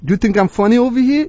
l_youthinkimfunny.wav